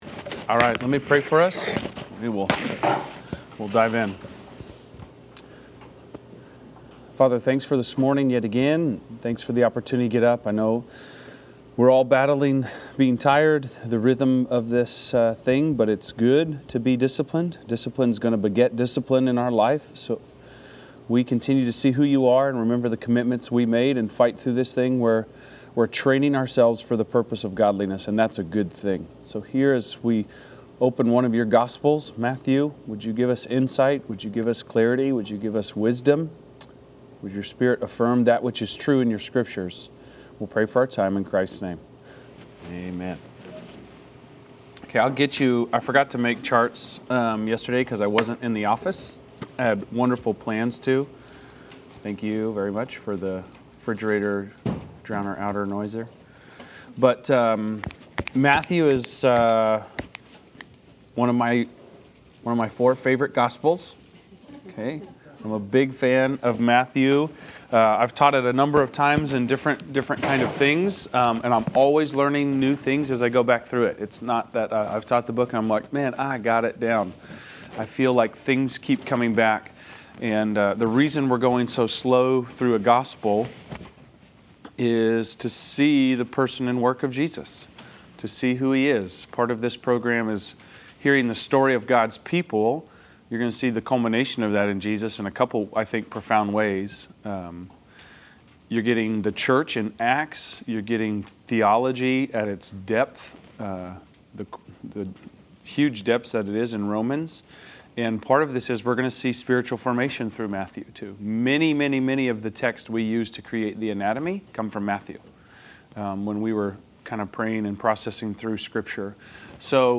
Class Session Audio October 15